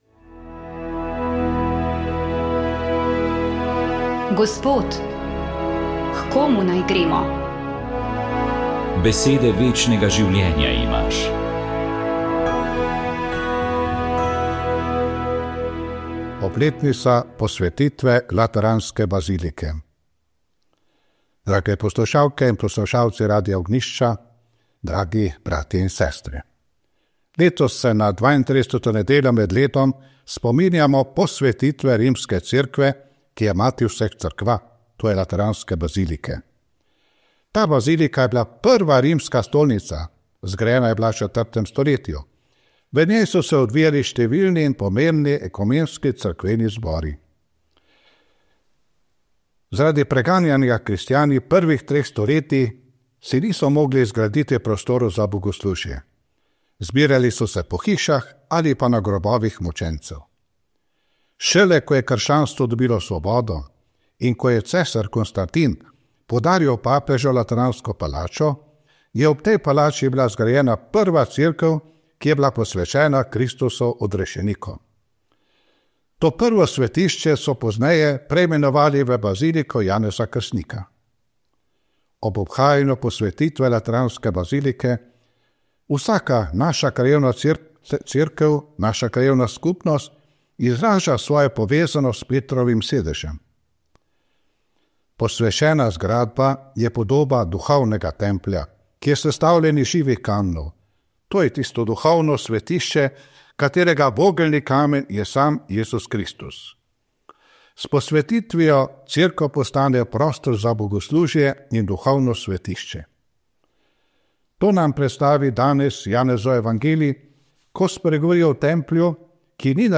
Duhovni nagovor
Tako je maribosrki nadškof Alojzij Cvikl začel tokratno razmišljanje ob evangelijskem odlomku četrte nedelje med letom.